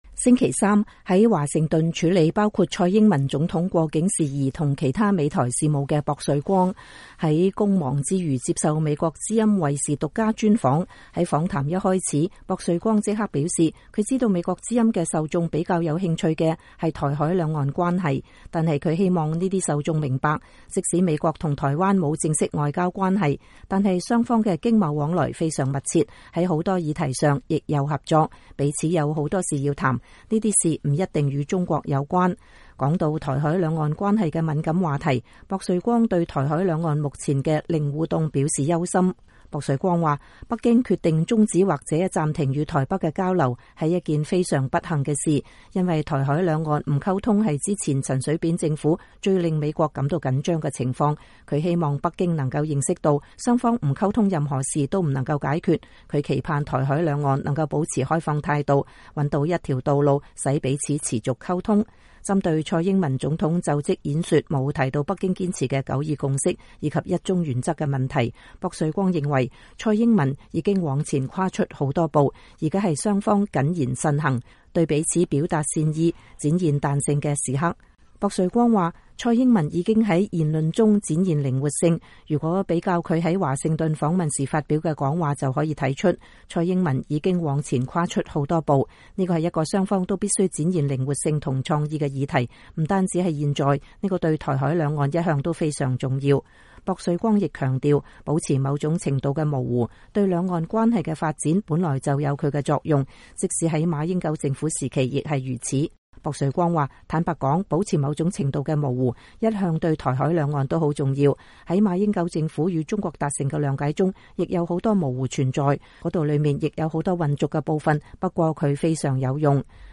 專訪薄瑞光:台海兩岸保持某種模糊有其作用
美國在台協會主席薄瑞光22日接受美國之音VOA衛視獨家專訪